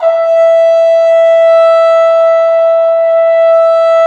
Index of /90_sSampleCDs/Roland L-CDX-03 Disk 2/BRS_Cup Mute Tpt/BRS_Cup Ambient